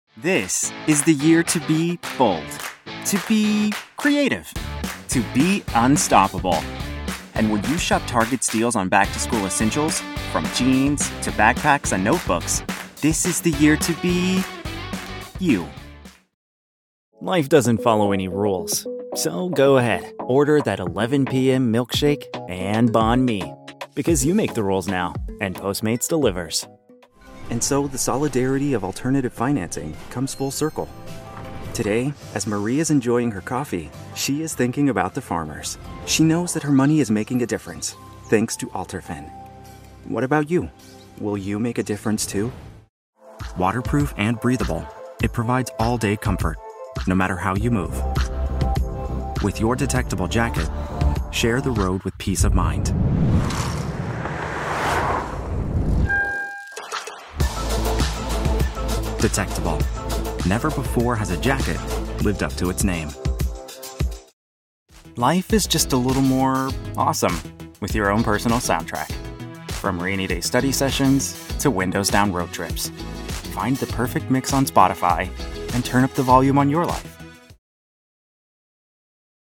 20 Something , 30 Something , Articulate , Bright , Male